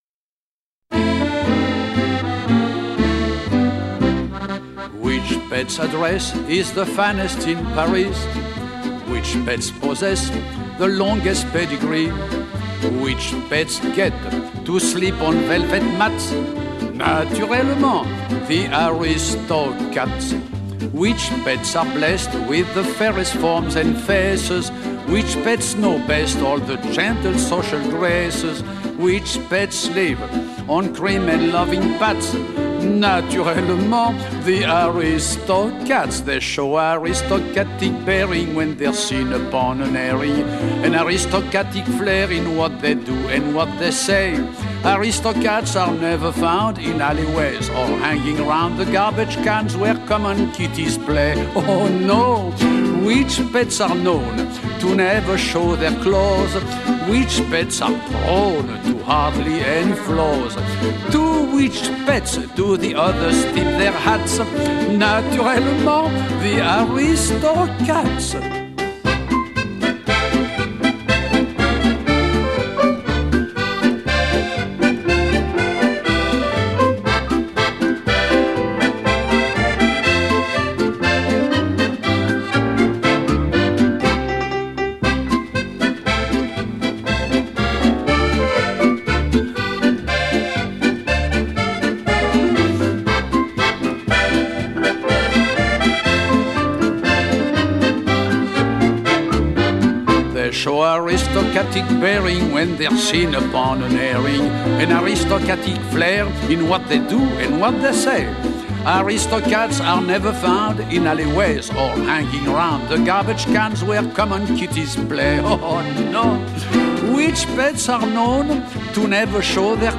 Soundtrack, Children's